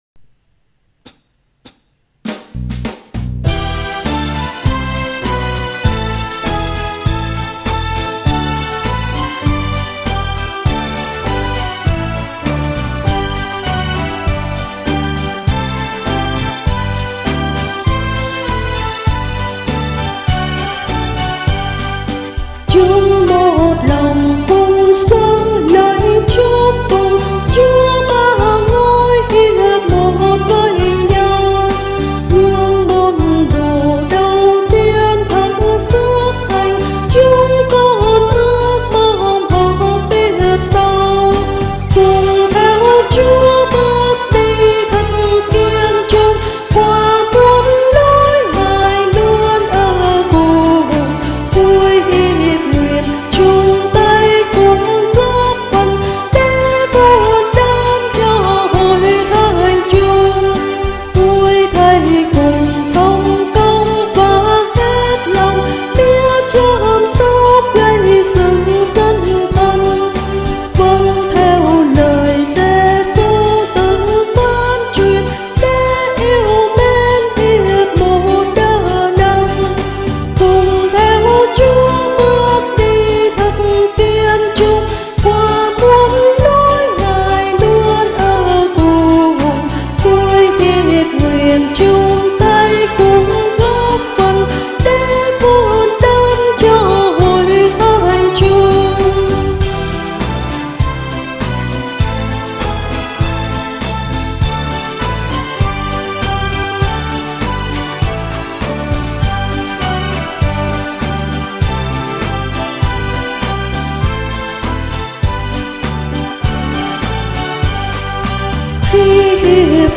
Audio Nhạc Thánh